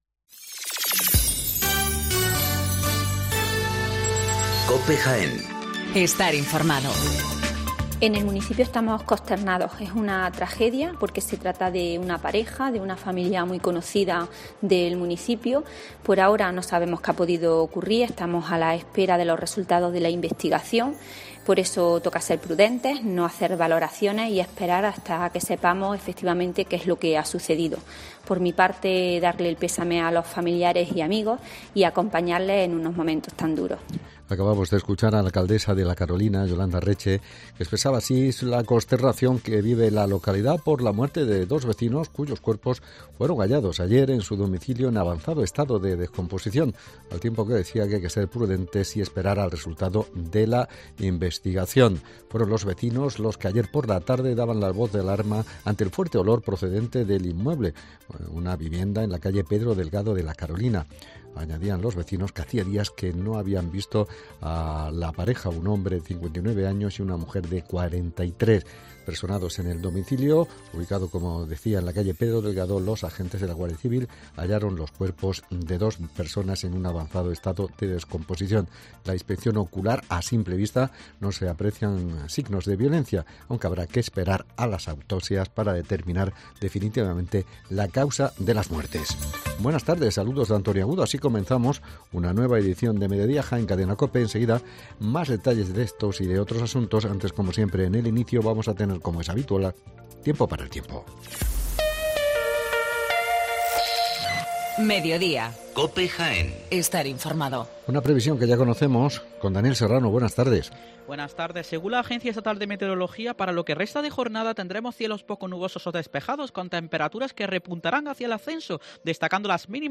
Toda la actualidad, las noticias más próximas y cercanas te las acercamos con los sonidos y las voces de todos y cada uno de sus protagonistas.